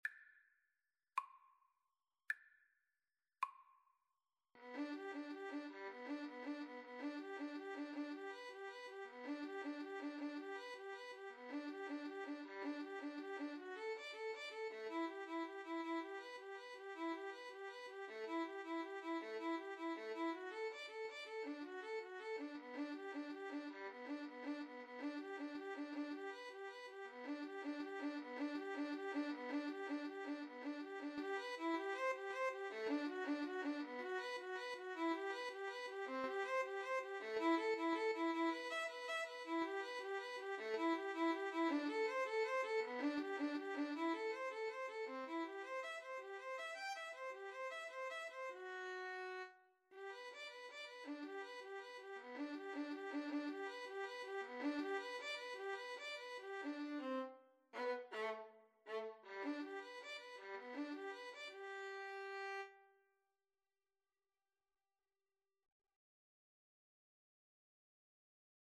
G major (Sounding Pitch) (View more G major Music for Violin Duet )
6/8 (View more 6/8 Music)
Andante ingueno
Violin Duet  (View more Intermediate Violin Duet Music)
Classical (View more Classical Violin Duet Music)